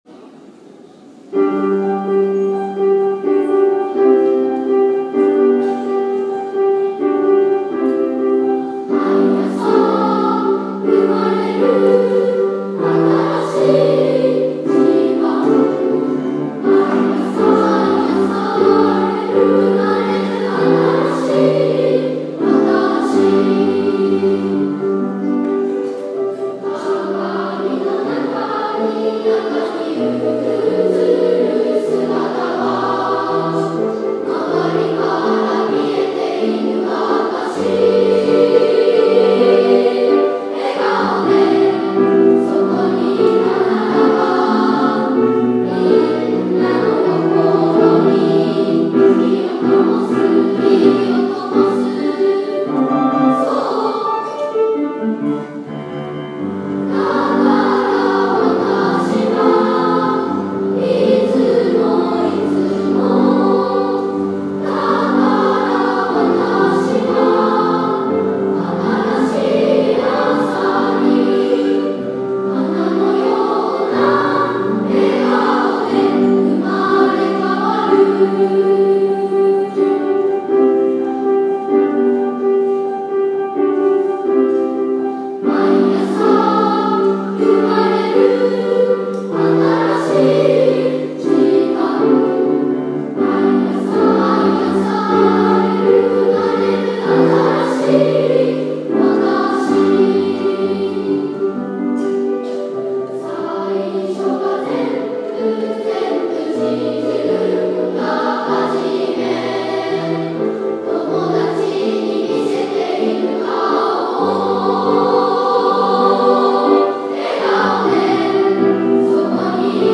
2016年6月4日（土）ふれあい科　１１ｔｈ大空創立記念コンサート
いつも新しい朝に」２部合唱です。
時計が時を刻んでいるようなピアノの前奏から、朝が来るたびに新しい自分に生まれ変わっていく自分たち。
その気持ちの高まりを最大限に発揮したのは最後のアカペラでした♪